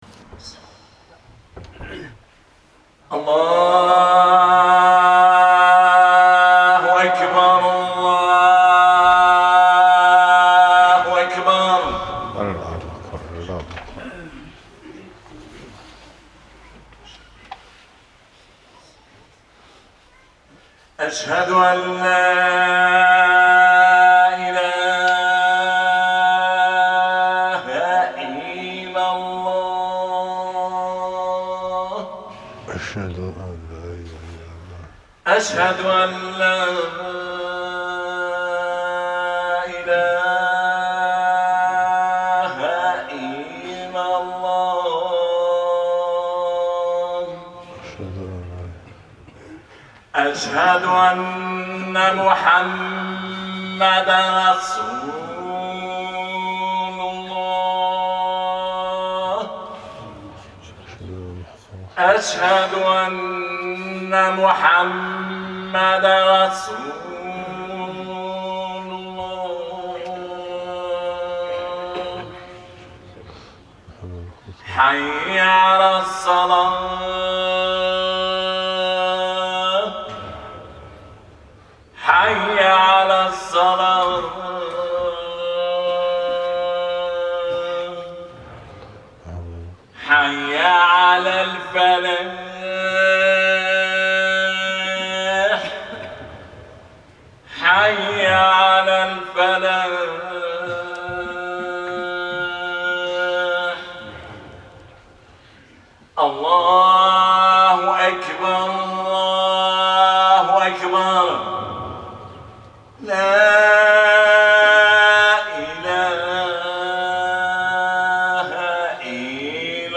260109_1249 Khutba Révélation du Coran.MP3